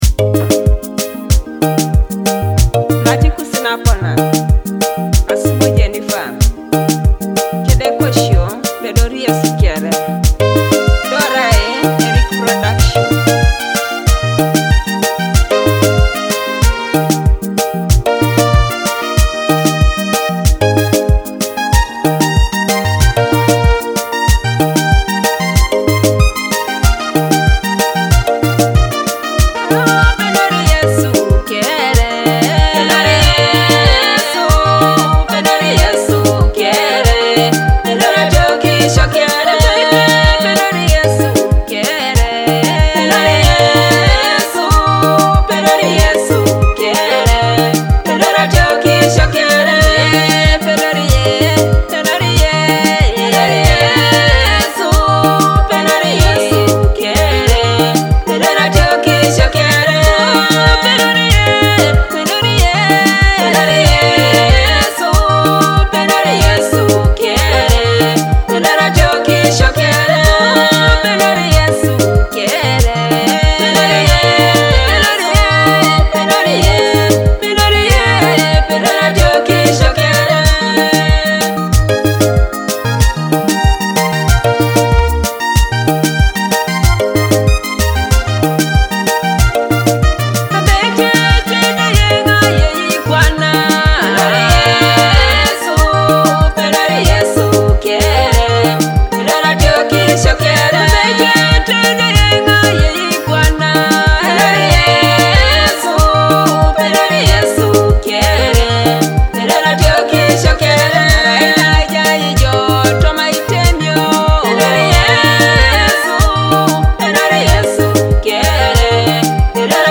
powerful gospel song